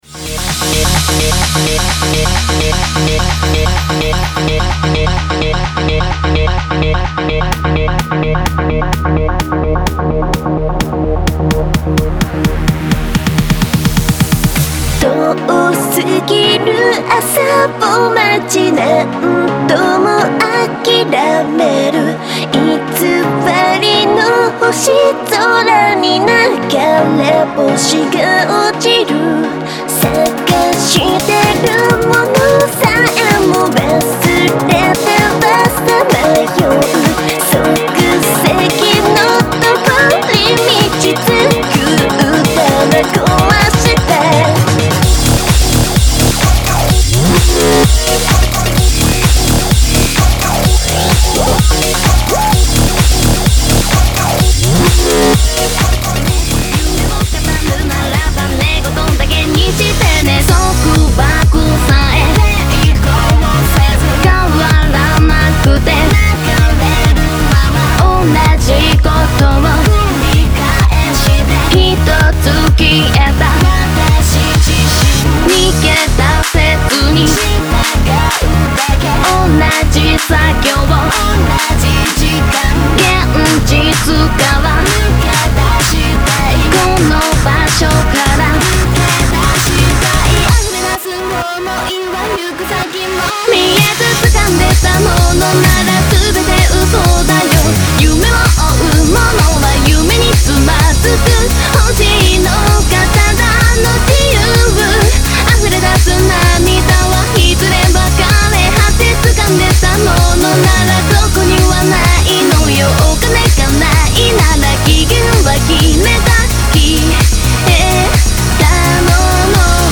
crossfade
Genre: Electro-House, Vocal